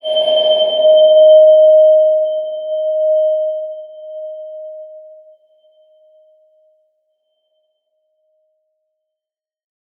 X_BasicBells-D#3-pp.wav